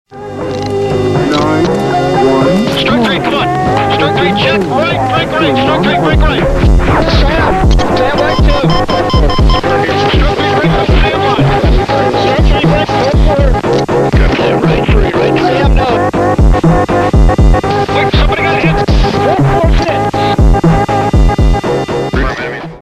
Ukrainian MiG-29 firing an AGM-88